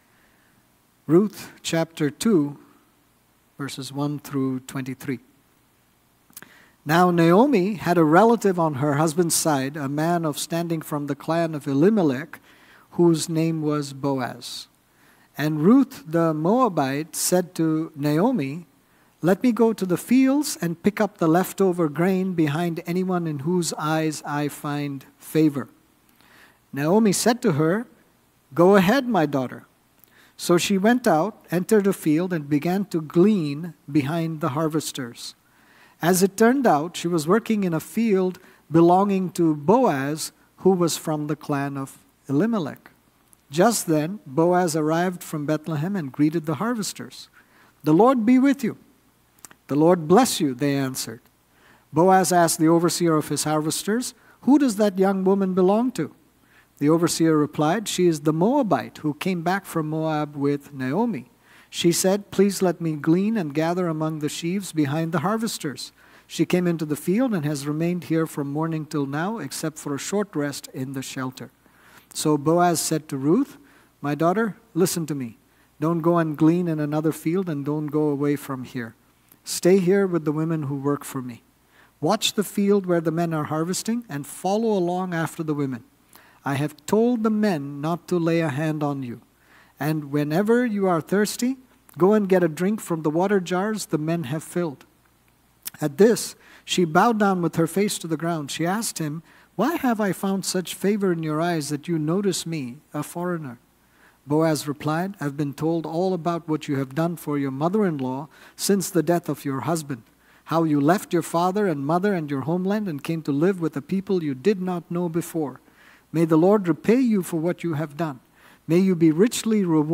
This sermon on Ruth 2:1-23 was preached